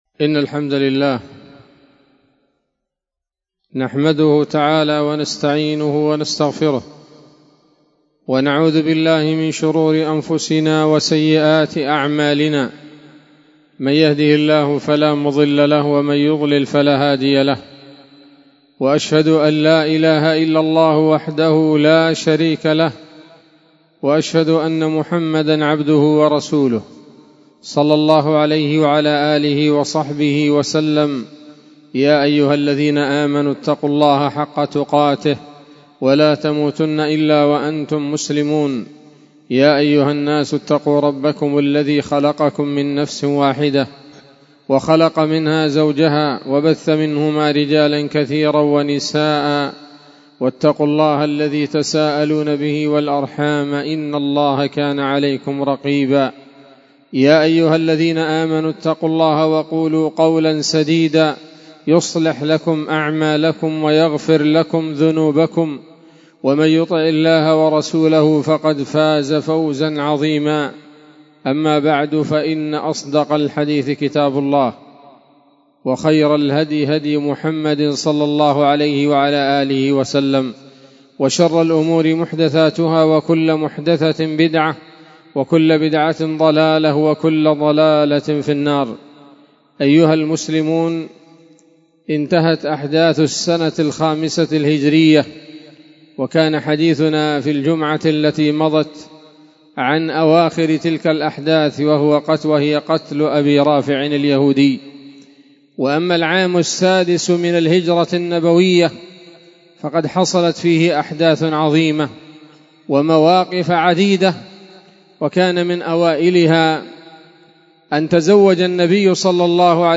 خطبة جمعة بعنوان: (( السيرة النبوية [23] )) 23 ذي القعدة 1445 هـ، دار الحديث السلفية بصلاح الدين